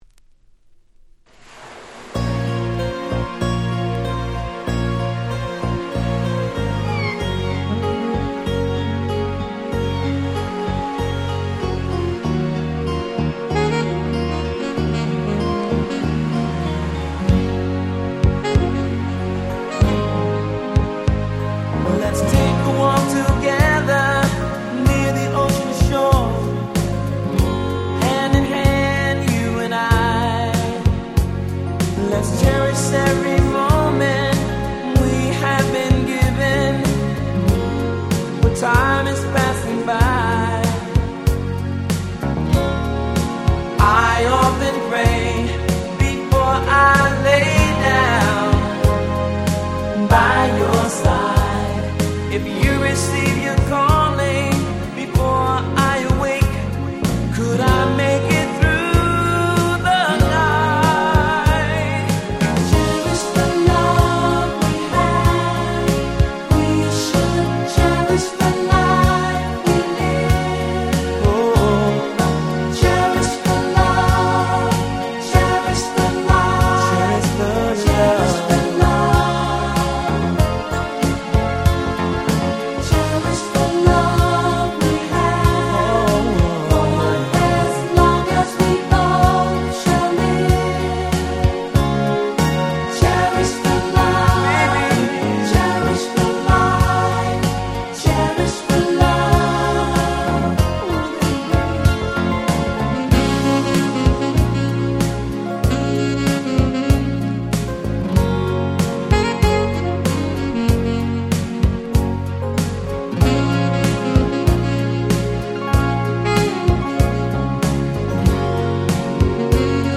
84' Super Hit Soul !!